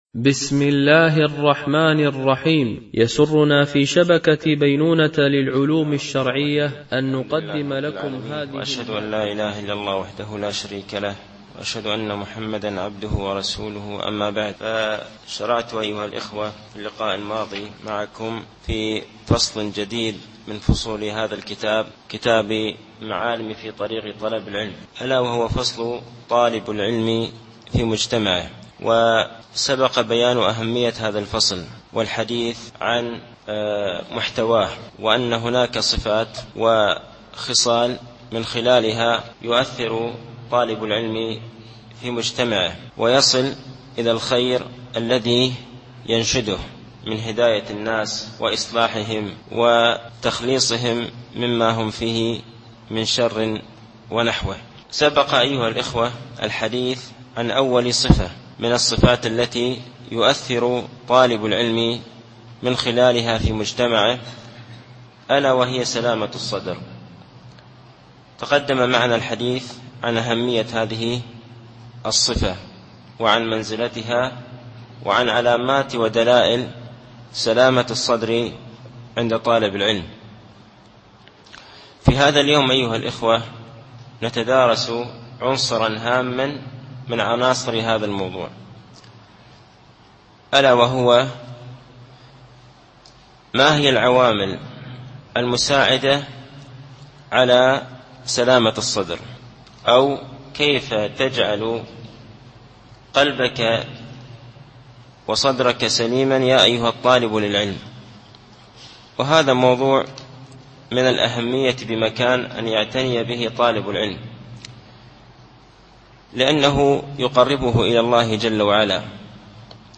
التعليق على كتاب معالم في طريق طلب العلم (طالب العلم وسلامة الصدر2) - الدرس السادس